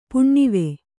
♪ puṇṇive